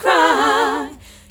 Cry Group-D.wav